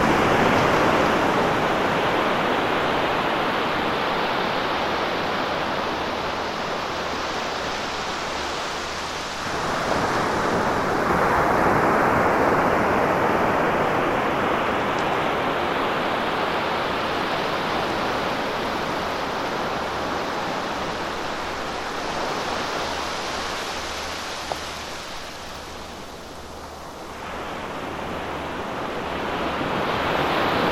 Le deuxième numéro de cette collection 100% nature propose un véritable concert de clapotis des vagues pris en direct à la tombée de la nuit sur les côtes de la Manche à proximité du lieu hautement symbolique qu'est le Mont Saint-Michel !